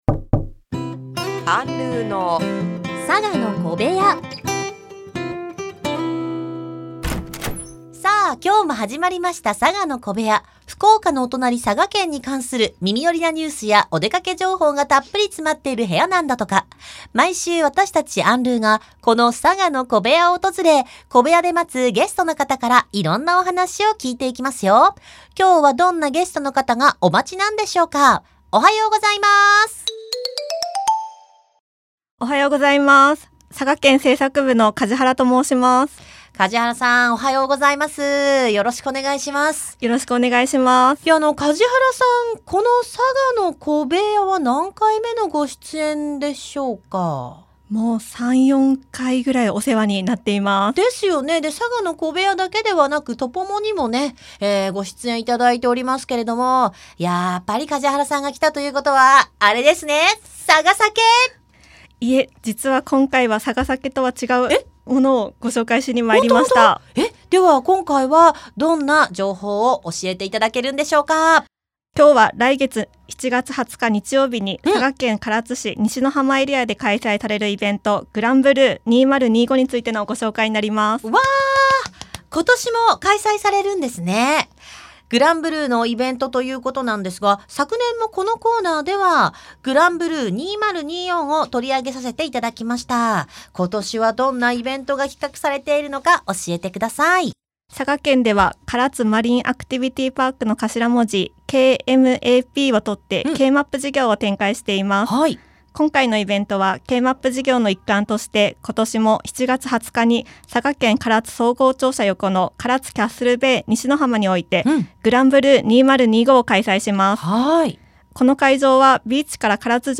小部屋で待つゲストの方から色んなお話しを聞いていきますよ？！